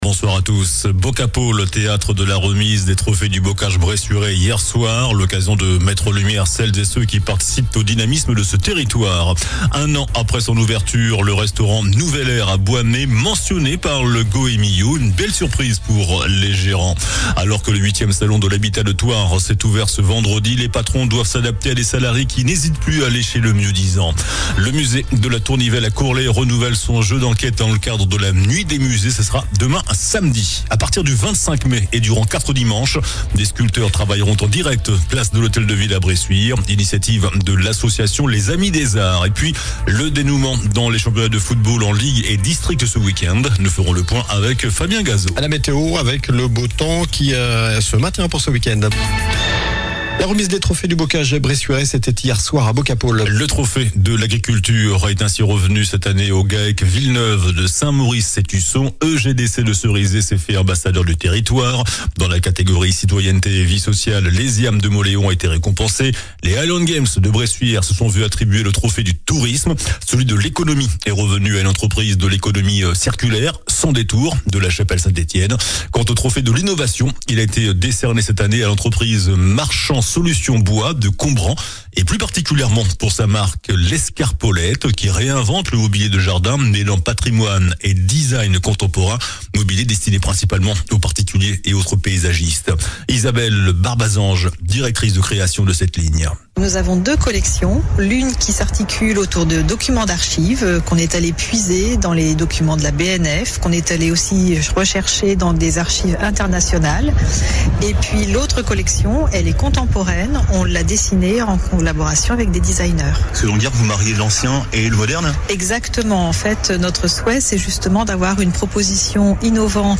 JOURNAL DU VENDREDI 16 MAI ( SOIR )